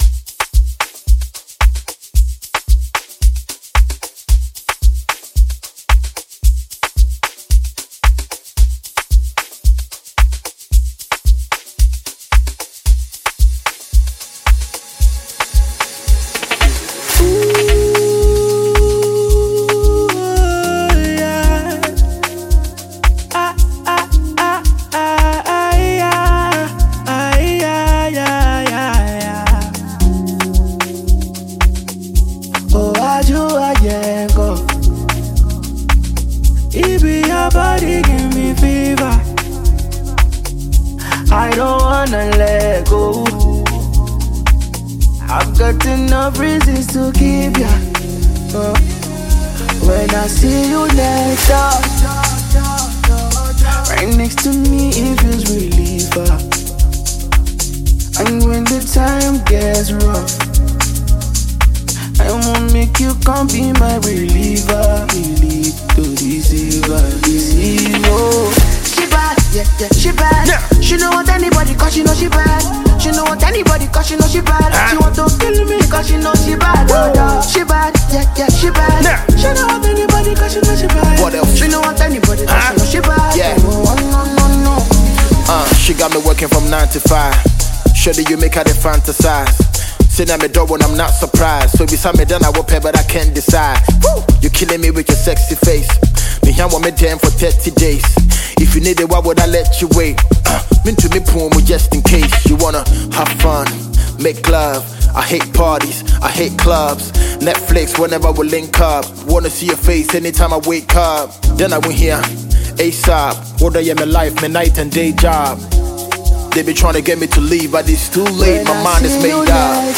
Ghana Music
Ghanaian rapper